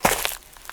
High Quality Footsteps
STEPS Leaves, Walk 13.wav